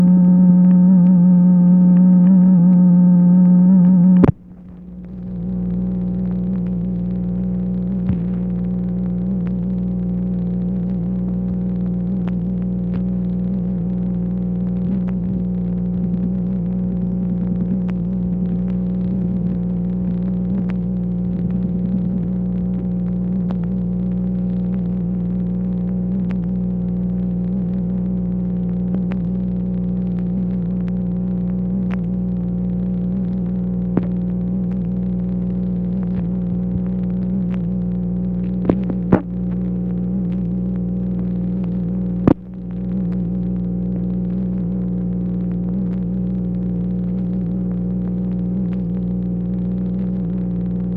MACHINE NOISE, February 10, 1964
Secret White House Tapes | Lyndon B. Johnson Presidency